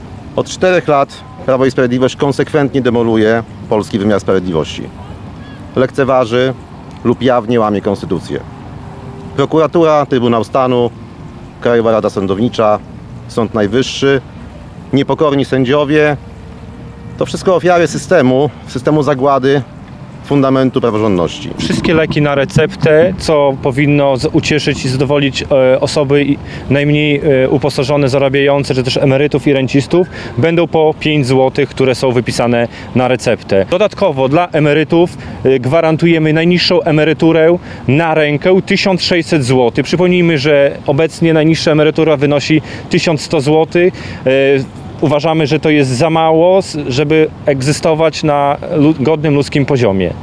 Podczas konferencji prasowej na Placu Marii Konopnickiej mówili o sobie i założeniach programowych. Podczas wystąpień zarzucali rządowi Prawa i Sprawiedliwości łamanie praworządności, mówili o problemach służby zdrowia, obiecywali wszystkie leki na receptę za 5 złotych i wyższe świadczenia emerytalne.